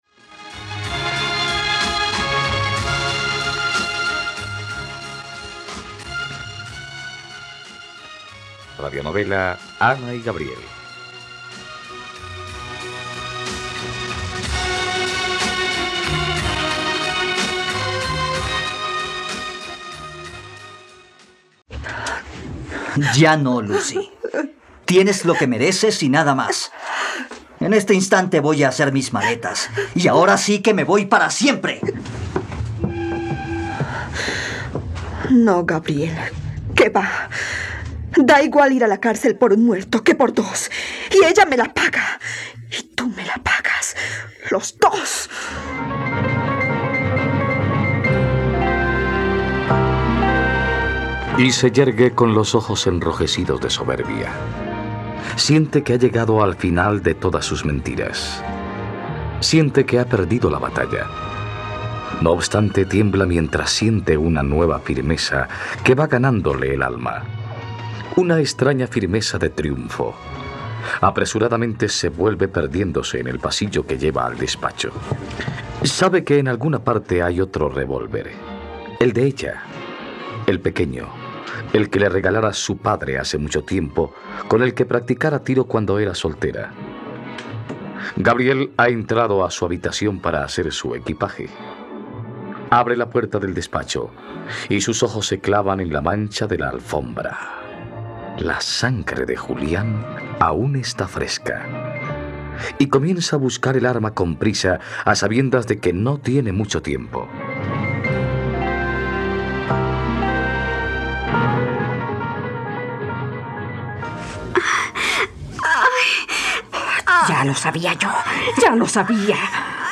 Ana y Gabriel - Radionovela, capítulo 97 | RTVCPlay